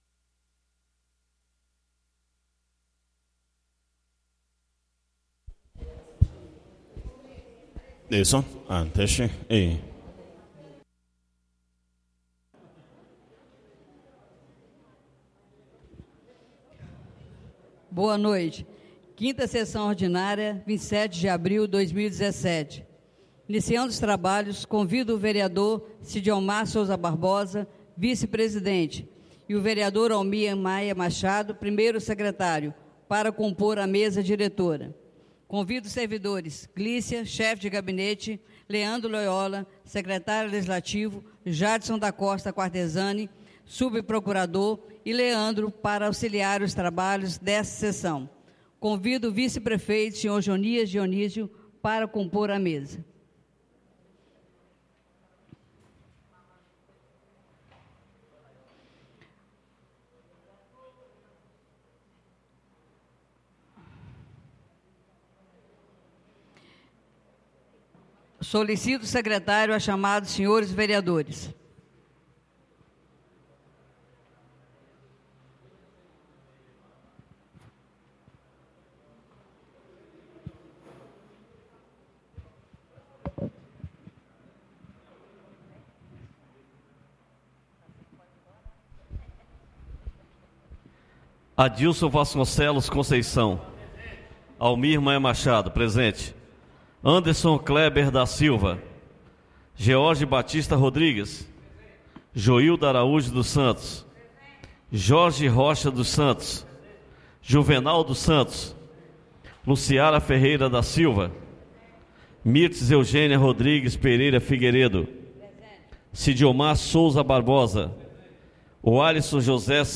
5ª (QUINTA) SESSÃO ORDINÁRIA DO DIA 27 DE ABRIL DE 2017 BRAÇO DO RIO